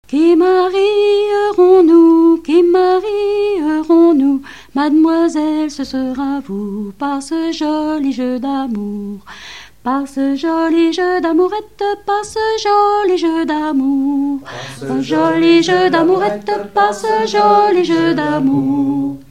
Rondes enfantines à baisers ou mariages
Pièce musicale inédite